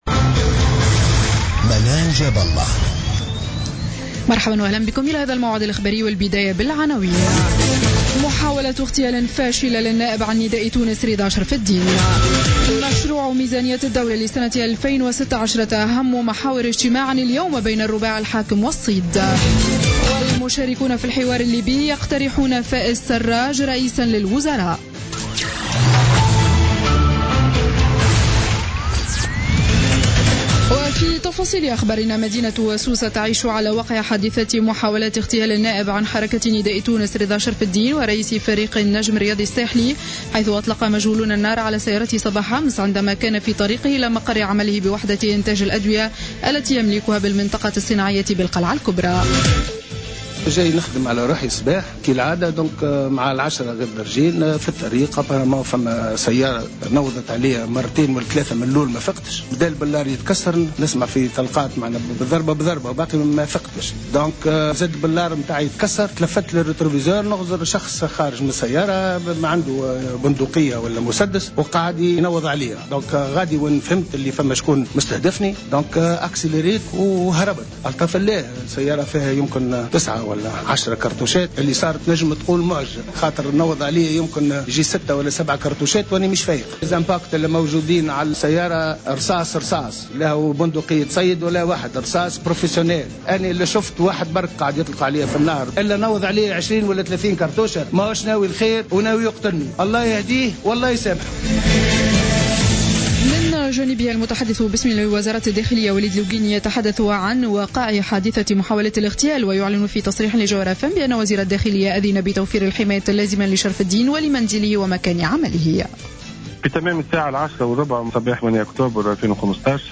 نشرة أخبارمنتصف الليل ليوم الجمعة 9 أكتوبر 2015